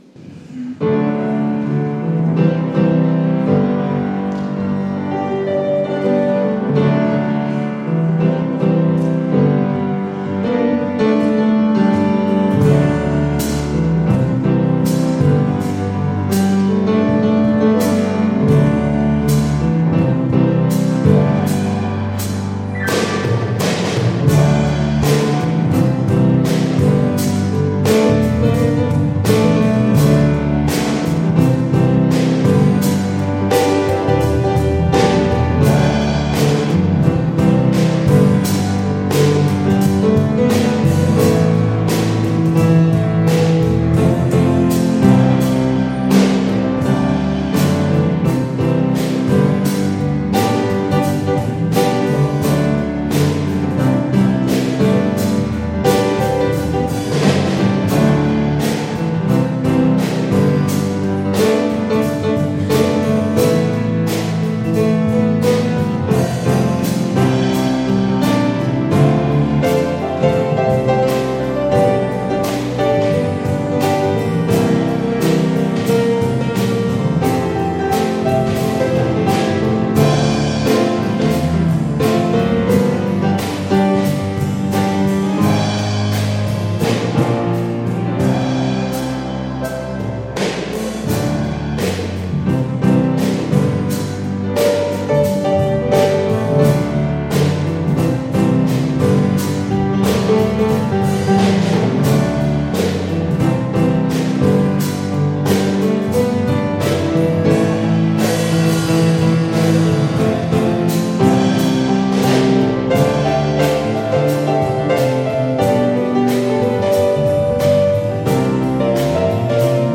Gottesdienst mit Zeitzünderchor | Bethel-Gemeinde Berlin Friedrichshain